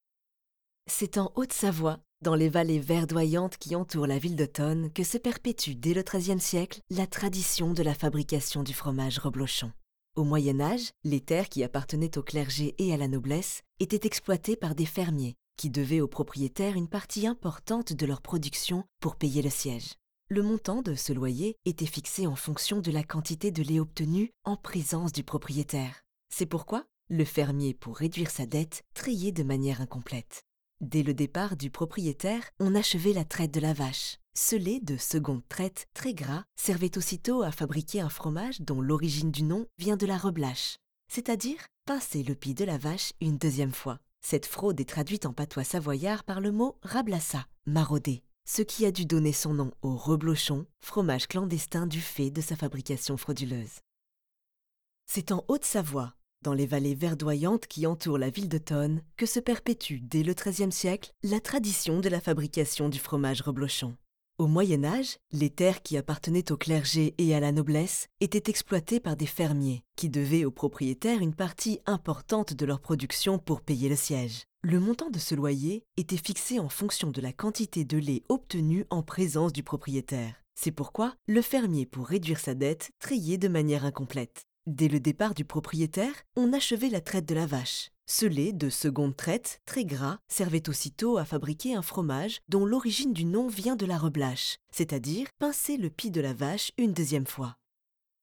Commercial, Natural, Warm, Soft, Accessible